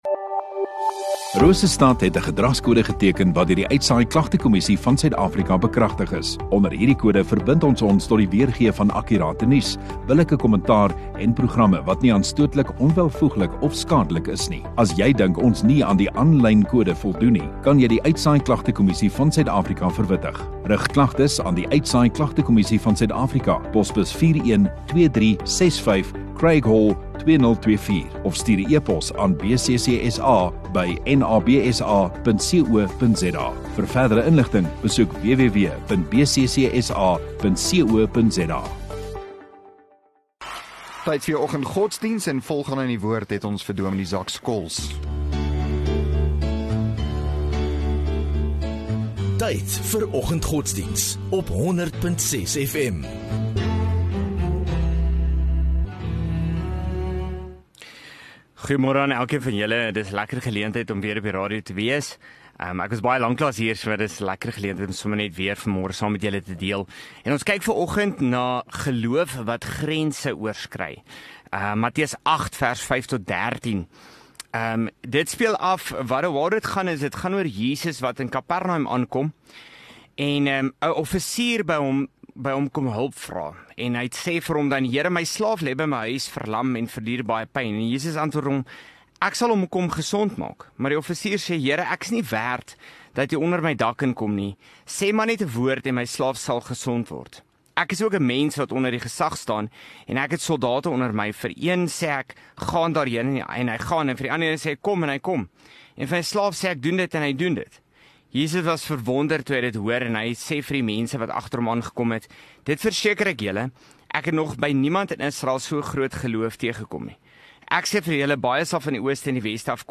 8 Dec Maandag Oggenddiens